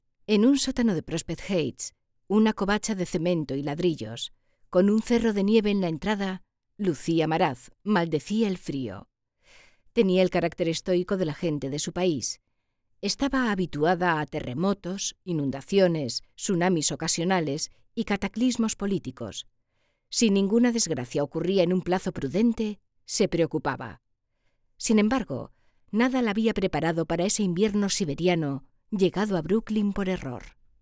Locutora y actriz de doblaje.
Sprechprobe: eLearning (Muttersprache):